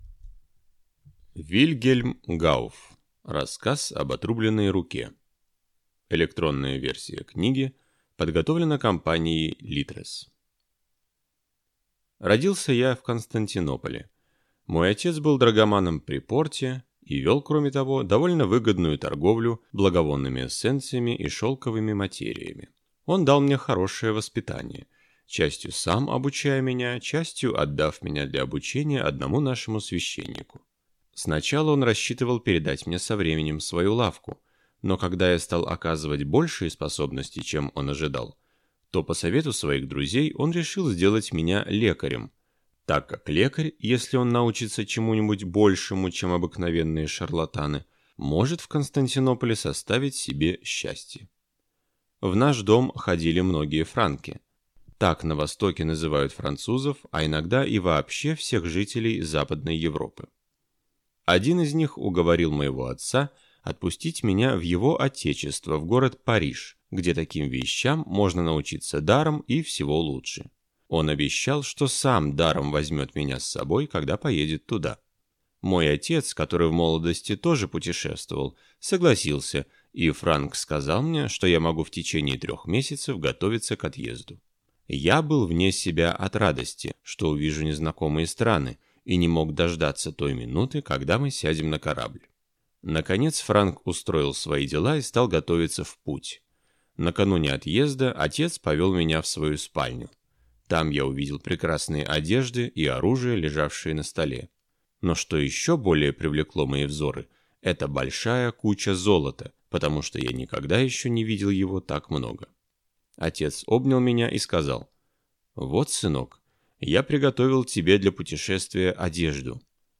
Аудиокнига Рассказ об отрубленной руке | Библиотека аудиокниг